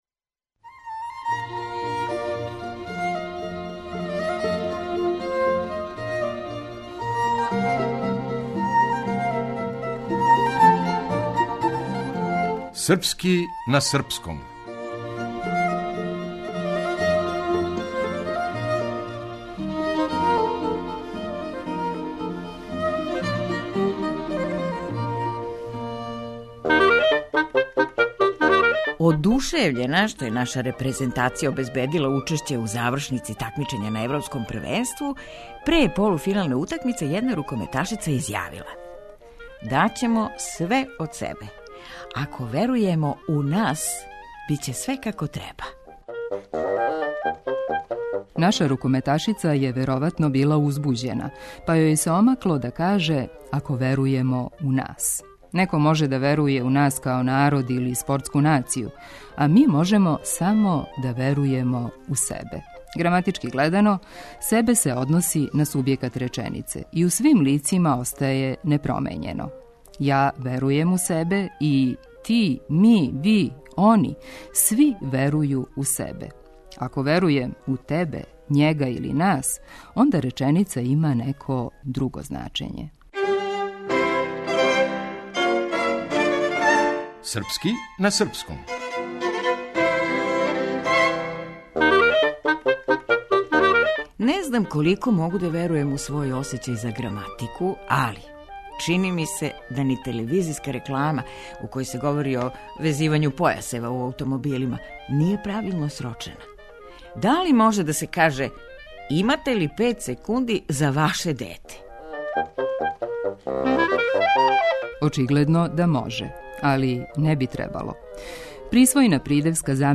Драмски уметник: